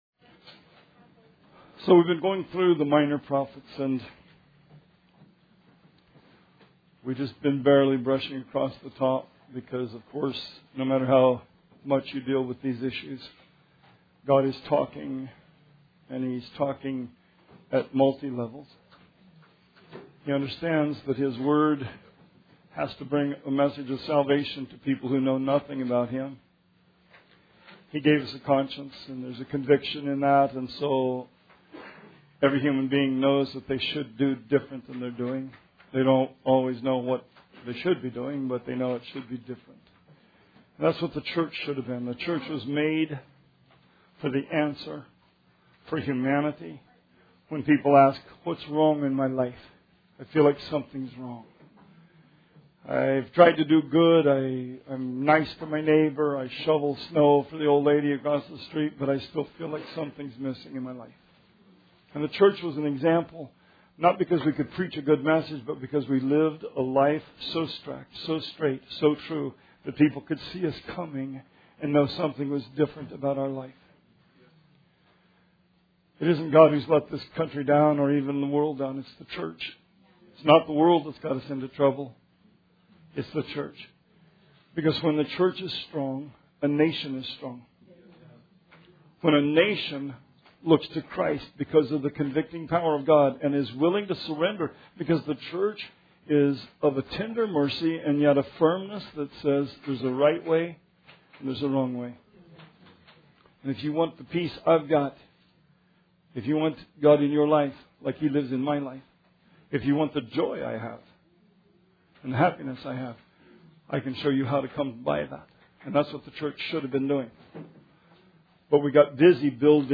Sermon 10/22/17